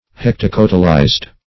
Search Result for " hectocotylized" : The Collaborative International Dictionary of English v.0.48: Hectocotylized \Hec`to*cot"y*lized\, a. (Zool.)